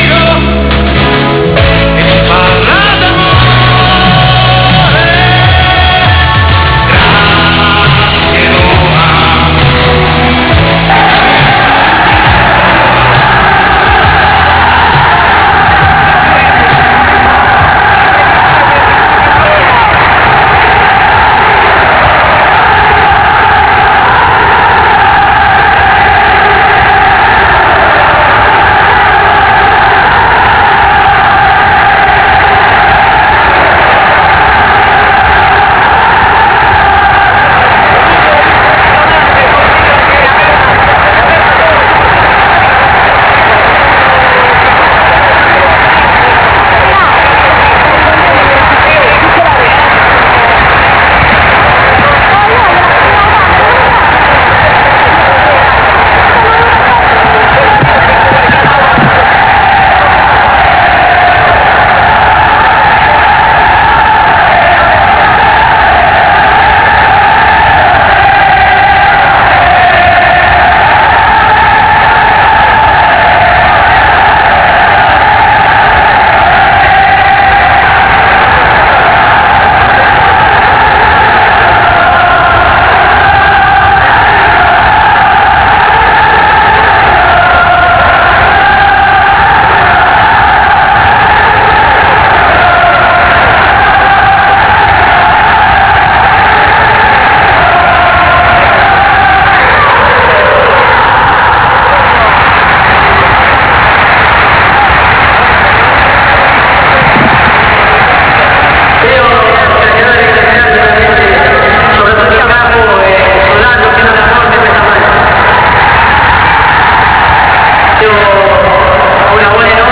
在奧林匹克球場內接受超過一萬三千名球迷的熱烈歡迎.
Bati, Bati, Bati, Batigol 羅馬現場版 (Wav, 1.3mb)